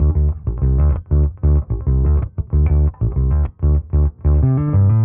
Index of /musicradar/dusty-funk-samples/Bass/95bpm
DF_PegBass_95-D.wav